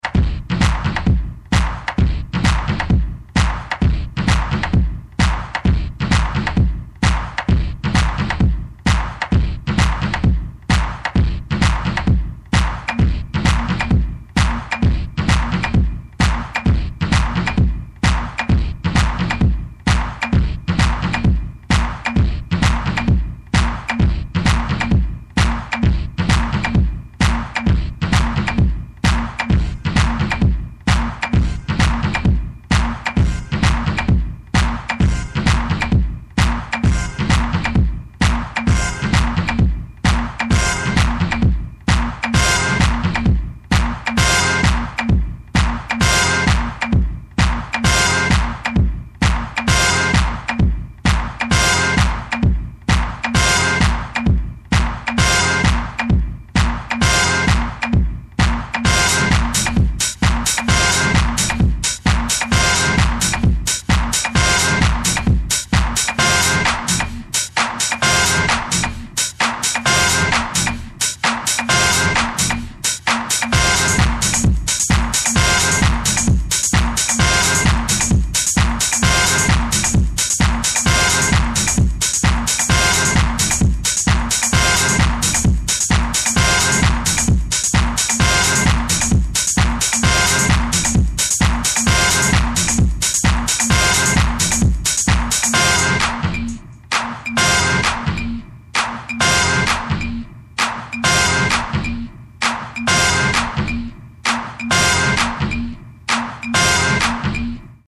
ホーム > TECHNO/ELECTRO > V.A.